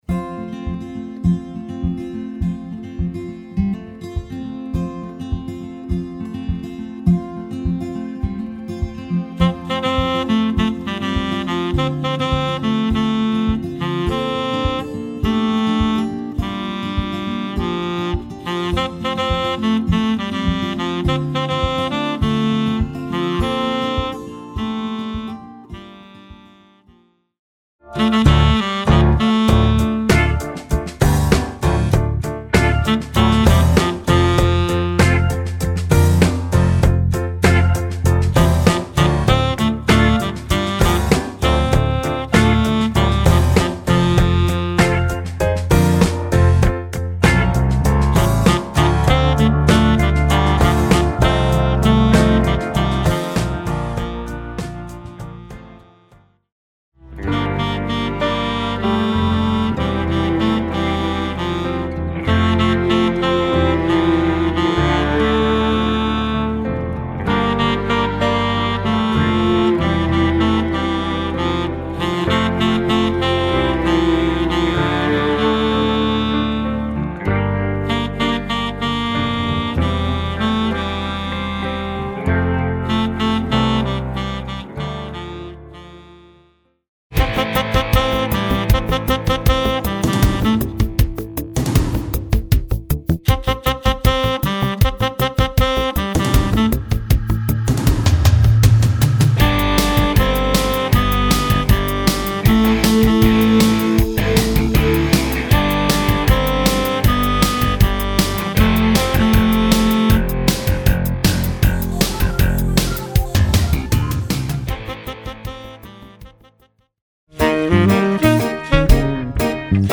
Voicing: Tenor Sax